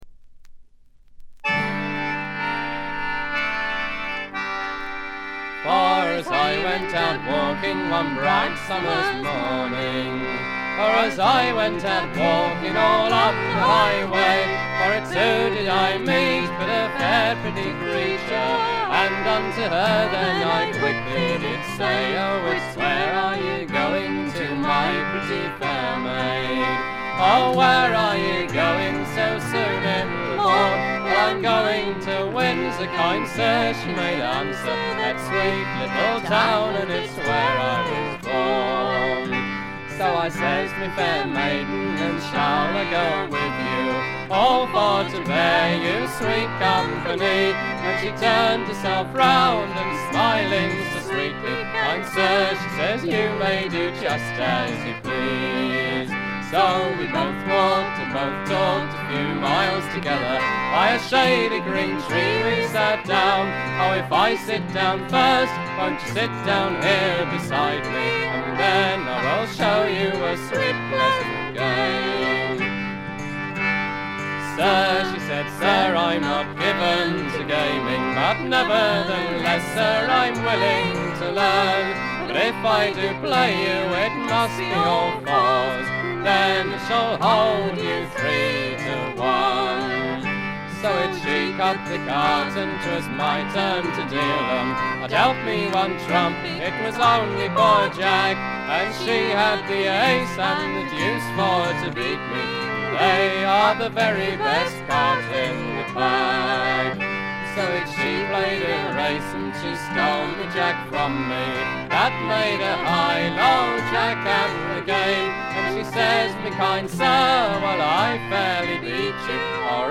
部分試聴ですが、静音部で軽微なチリプチが少し、散発的なプツ音が少し。
本作もトラッド・アルバムとして素晴らしい出来栄えです。
試聴曲は現品からの取り込み音源です。
Recorded At - Riverside Studios, London